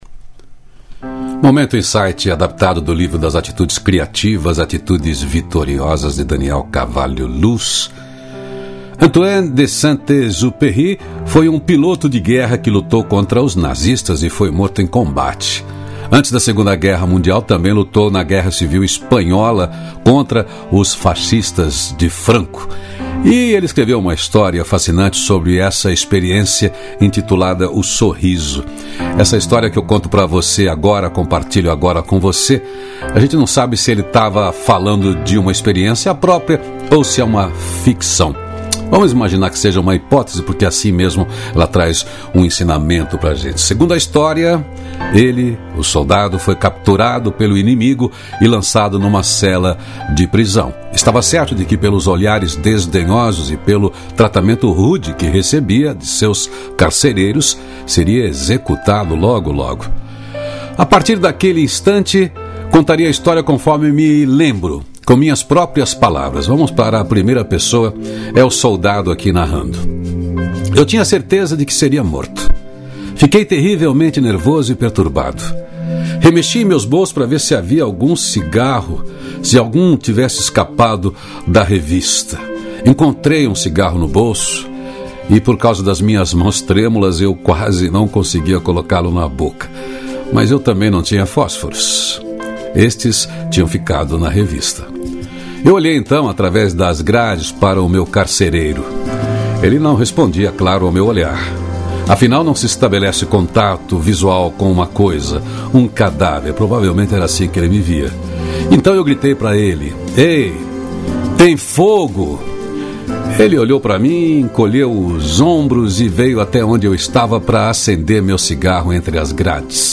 Música: Não há pedras no caminho… Banda Aquática Álbum: Nova Manhã